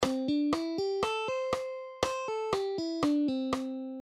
【ドレミソラ】と弾けばメジャーペンタトニックスケール。
Major Penta Tonic Scale
メジャーペンタ音源.mp3